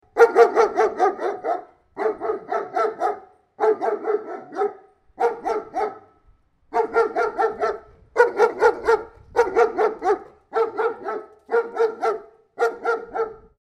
دانلود صدای پارس سگ وحشی از ساعد نیوز با لینک مستقیم و کیفیت بالا
جلوه های صوتی